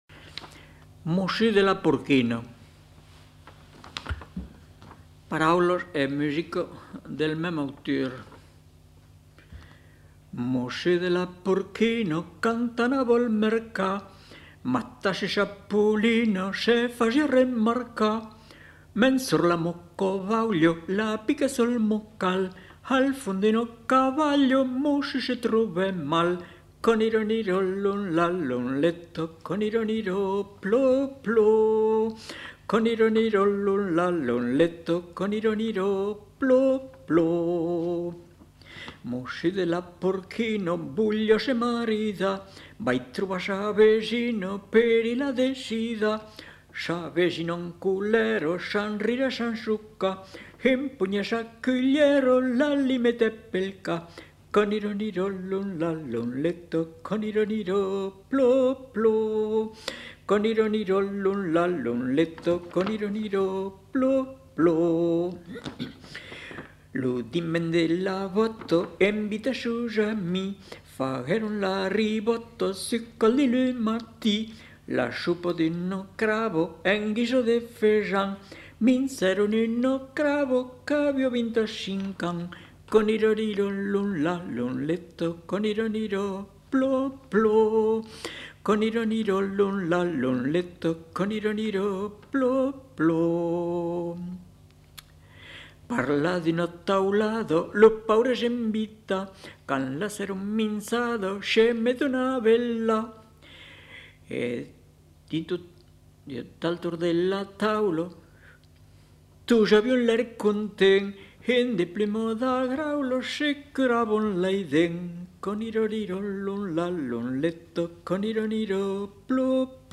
Aire culturelle : Périgord
Lieu : Lolme
Genre : chant
Effectif : 1
Type de voix : voix d'homme
Production du son : chanté